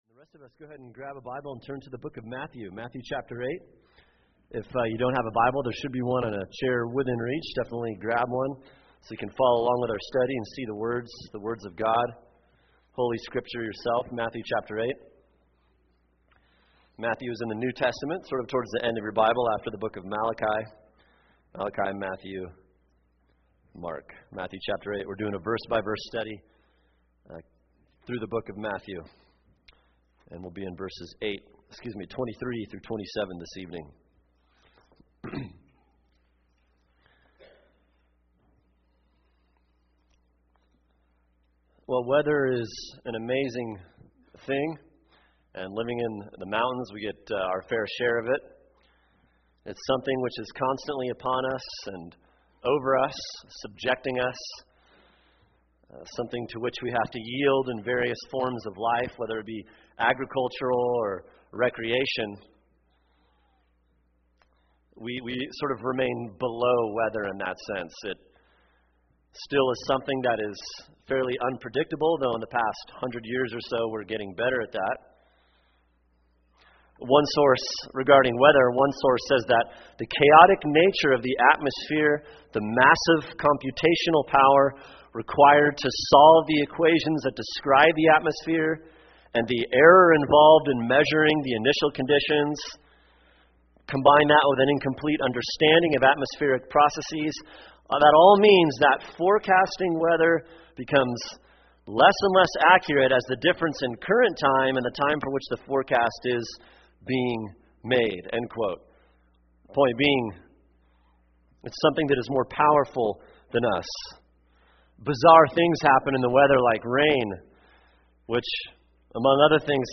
[sermon] Matthew 8:23-27 “The Lord of the Storm” | Cornerstone Church - Jackson Hole